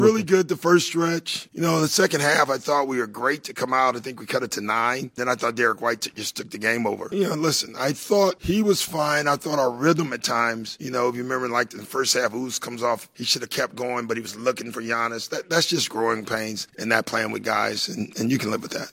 Bucks Doc Rivers talked about Giannis’ first game back.